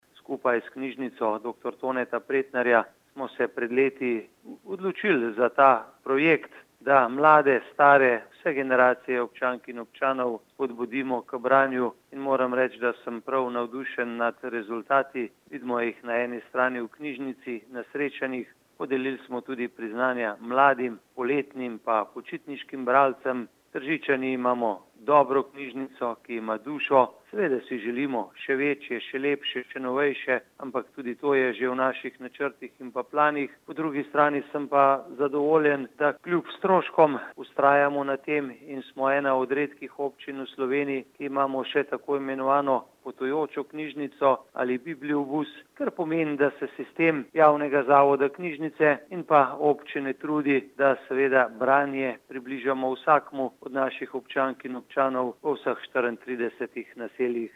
izjavazupanobcinetrzicmag.borutsajovicobranjuprijazniobcini.mp3 (1,4MB)